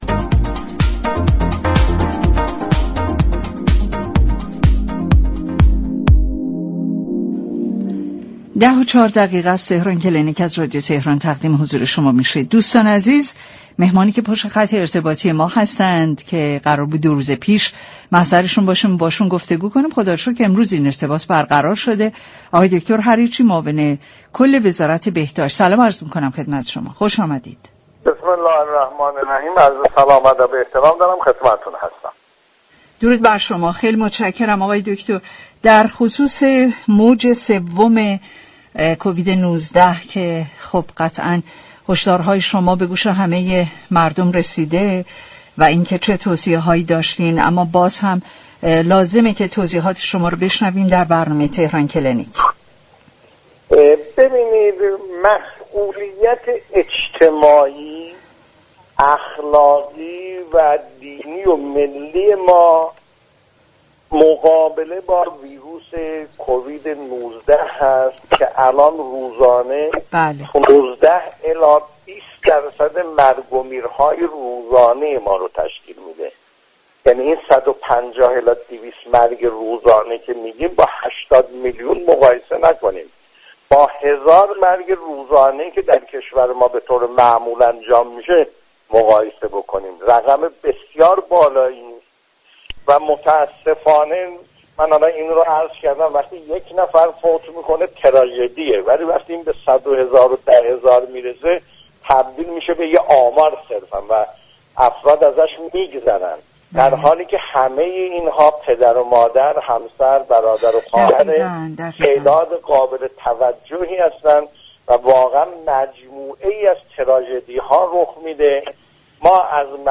دكتر ایرج حریرچی، معاون كل وزارت بهداشت در گفتگو با رادیو تهران، آخرین اطلاعات موجود درباره موج سوم كرونا، واكسن های آنفلوآنزا و كووید 19 را تشریح كرد.